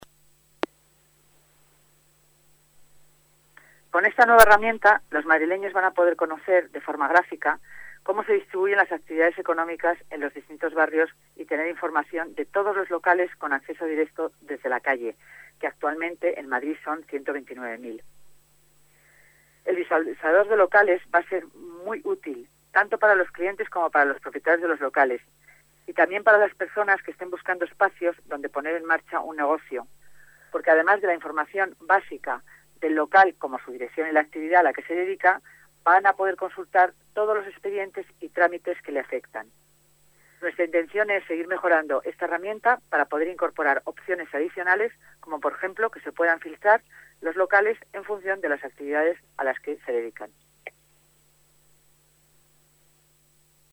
Nueva ventana:Declaraciones de la delegada de Economía, Hacienda y Administración Pública, Concepción Dancausa